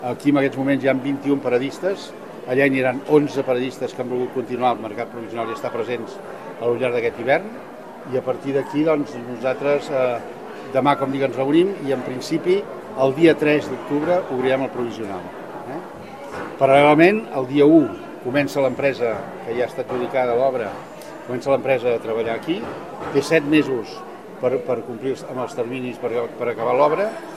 El regidor de Promoció Econòmica de l’Ajuntament de Palamós, Antoni Bachiller, afegeix que l’empresa encarregada de dur a terme l’obra del mercat té un termini de 7 mesos, des de l’1 d’octubre, per completar-la.